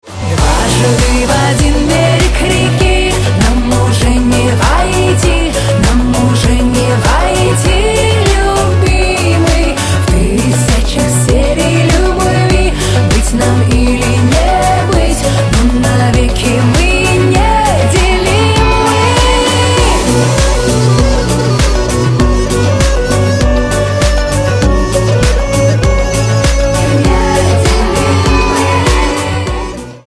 • Качество: 128, Stereo
поп
dance
восточные